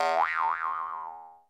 Sound clip of Spring Mario jumping in Super Mario Galaxy
SMG_Spring_Jump.wav